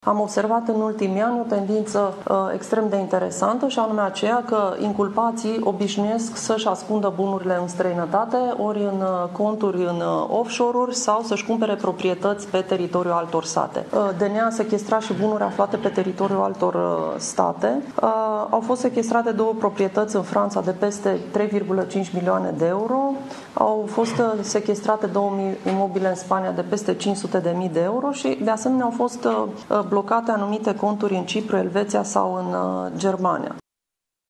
Procurorul şef al DNA, Laura Codruţa Kovesi, a mai declarat marți, la un seminar privind recuperarea prejudiciilor, că persoanele cercetate preferă să îli ascundă bunurile în străinătate sau să plece cu totul, menţionând că datele arată că infractorii preferă detenţia, dar să nu li se confişte averea.